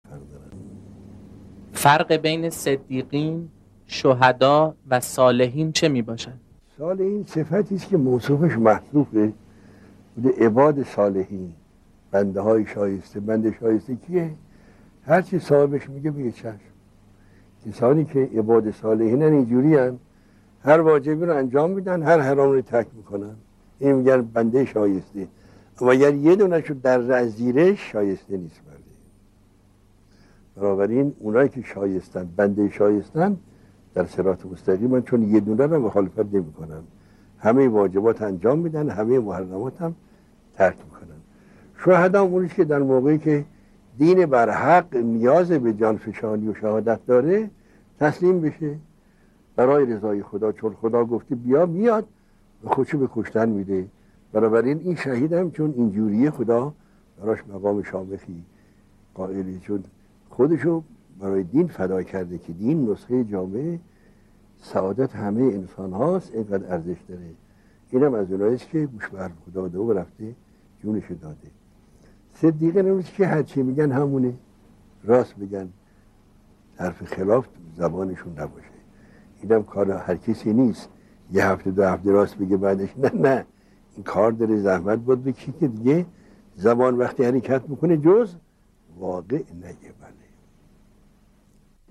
به گزارش خبرگزاری حوزه، مرحوم آیت الله عزیزالله خوشوقت از اساتید اخلاق حوزه در یکی از دروس اخلاق به پرسش و پاسخی پیرامون «فرق بین صدیق، شهید و صالح» پرداختند که متن آن بدین شرح است: